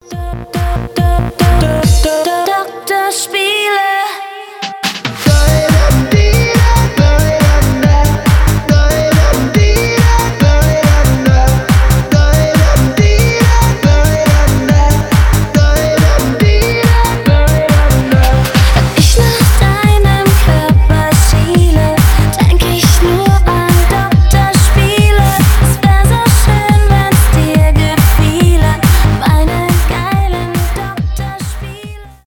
танцевальные
транс , евродэнс